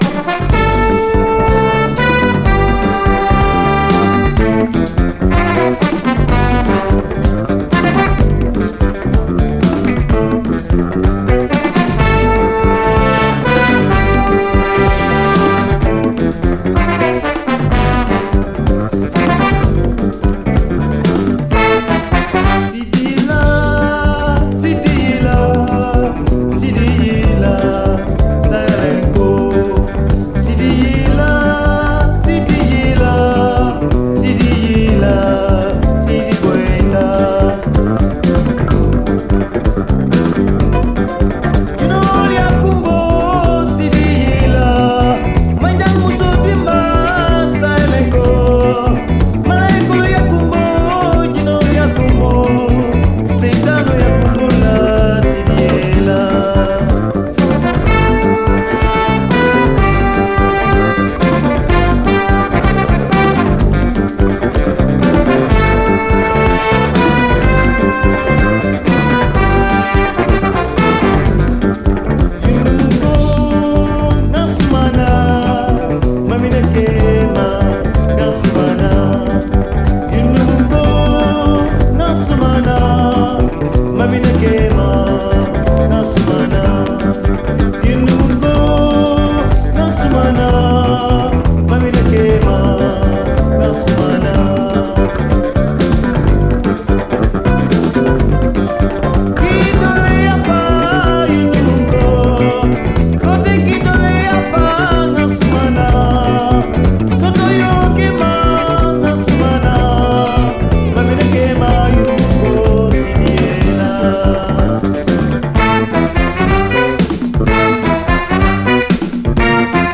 AFRO, WORLD
セネガルのアフロ・フュージョン・バンド！
柔らかなエレピ入りのメロウ・アフロ・フュージョン・ブギー